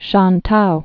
(shäntou) also Swa·tow (swätou)